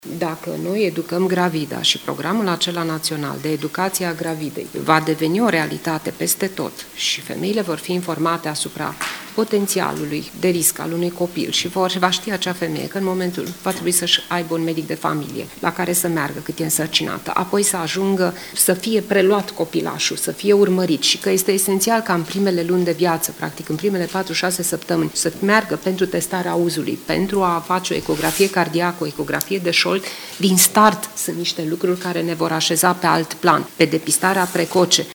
Președinta CNAS, Valeria Herdea: Este esențial ca mama, în primele luni de viață ale copilului, practic, în primele patru-șase săptămâni să meargă pentru testarea auzului